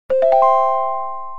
Pop_Up.aac